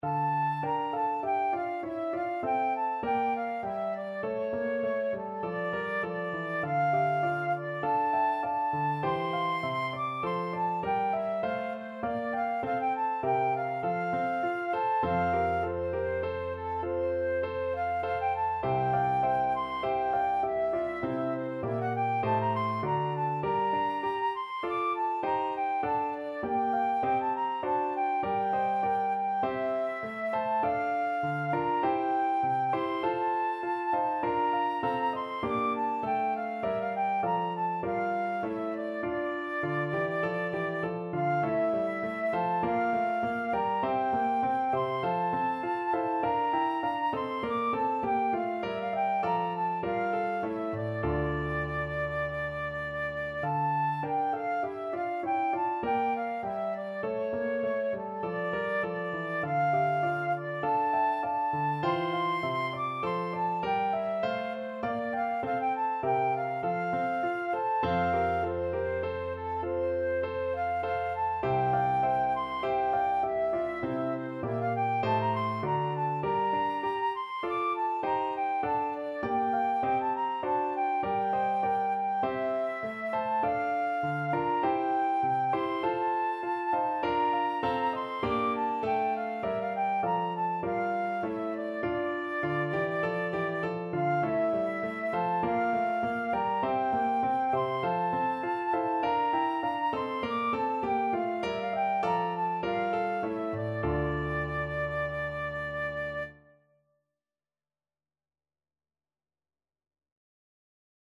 Flute
D minor (Sounding Pitch) (View more D minor Music for Flute )
4/4 (View more 4/4 Music)
Classical (View more Classical Flute Music)
if_music_be_FL.mp3